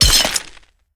box_glass_open-1.ogg